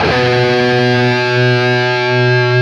LEAD B 1 CUT.wav